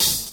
OpenHH.wav